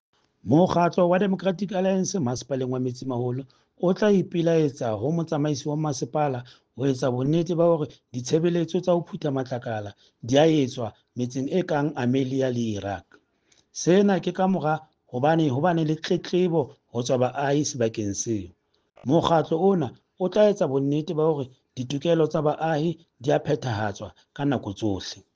Sesotho soundbites by Cllr Stone Makhema and